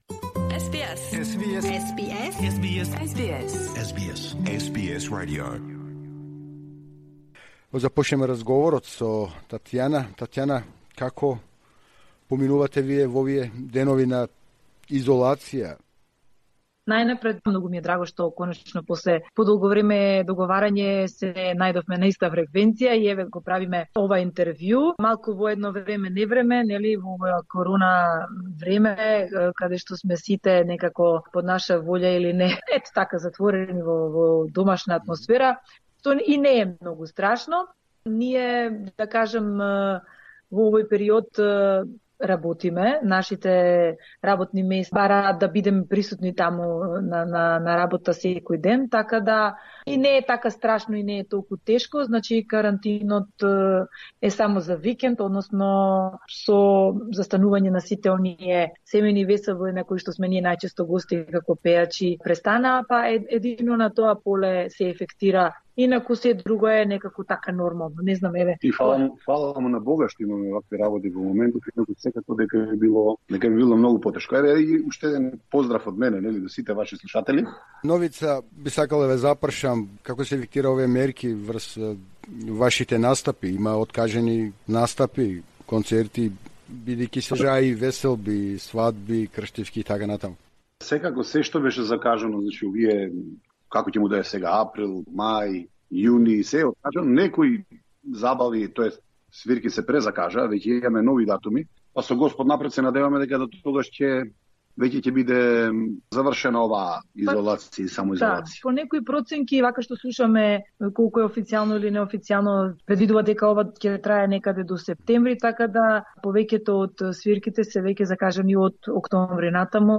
Во аудио разговорот за програмата на македонски јазик на СБС радио ќе можете да слушнете и за тоа како се приспособија на новиот живот во Австралија но и плановите што ги имаат во иднина.